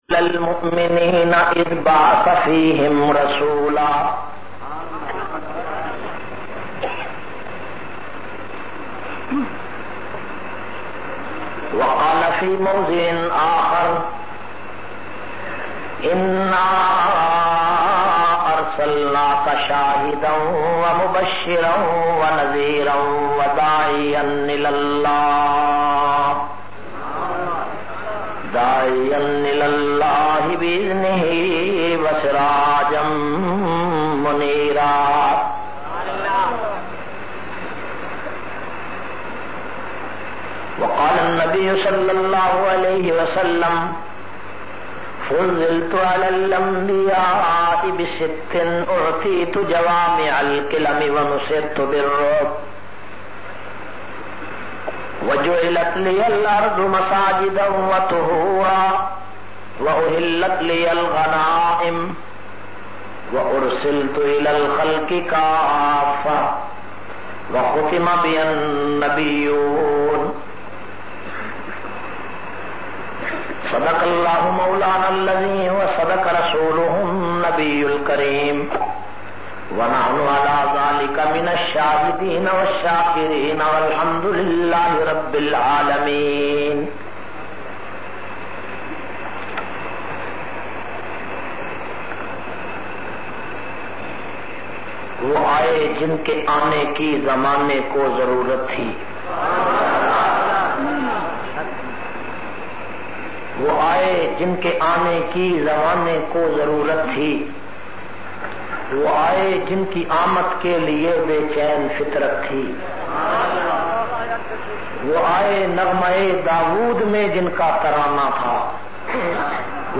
622- Seerat un Nabi Madrasa Dar ul Aloom Usmania Peoples Colony Multan.mp3